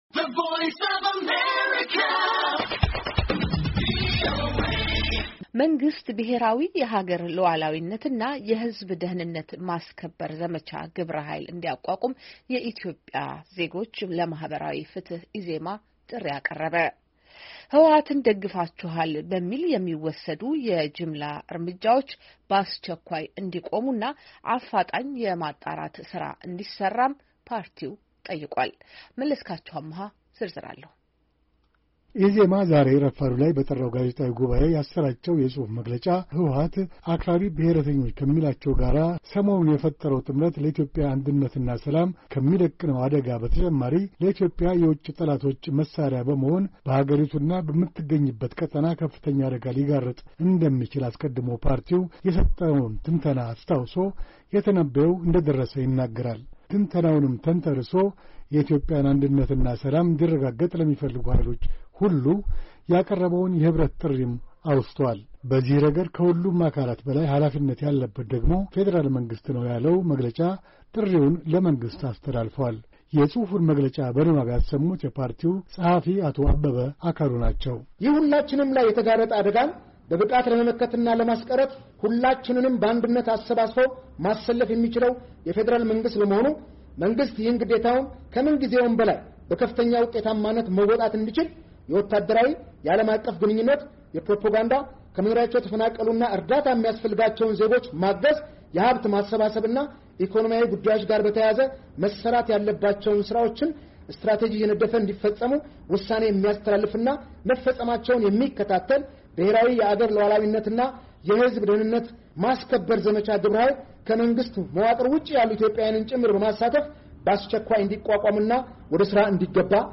የኢዜማ ጋዜጣዊ ጉባዔ